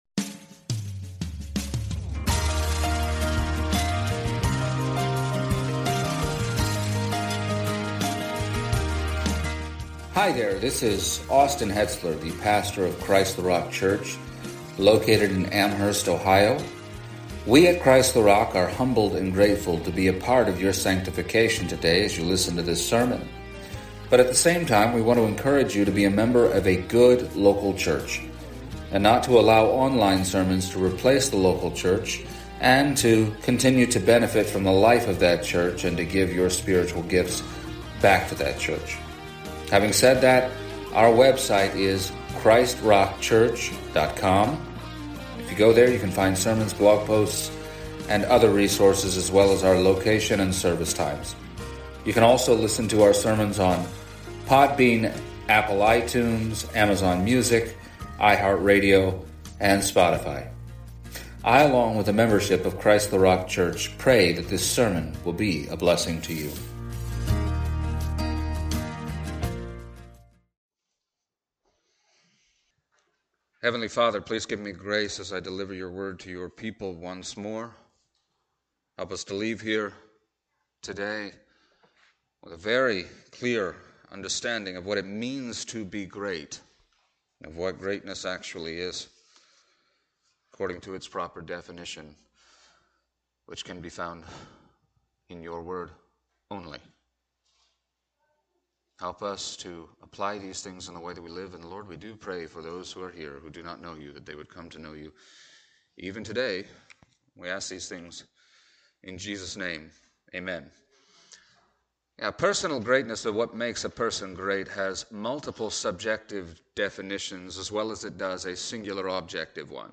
Passage: Acts 25:13-27 Service Type: Sunday Morning